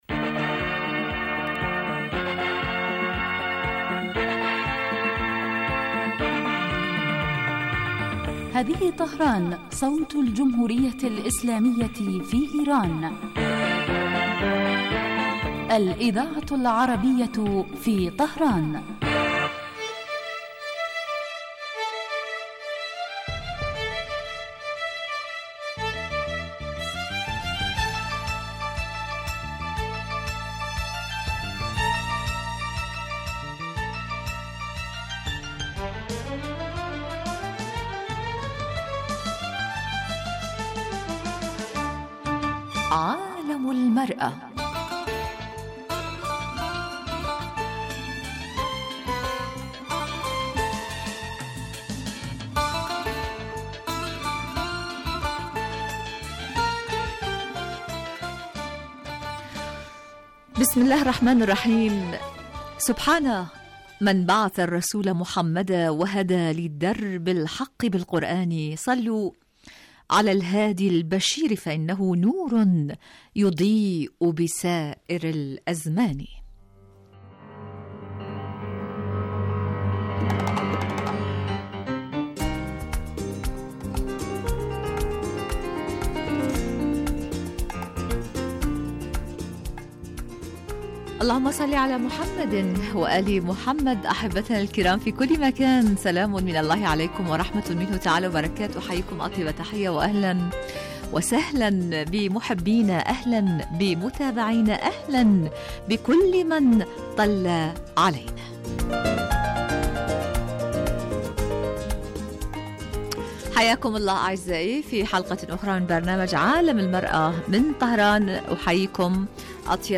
من البرامج الناجحة في دراسة قضايا المرأة في العالمين الإسلامي والعربي ومعالجة ما لها من مشكلات و توكيد دورها الفاعل في تطوير المجتمع في كل الصعد عبر وجهات نظر المتخصصين من الخبراء و أصحاب الرأي مباشرة علي الهواء.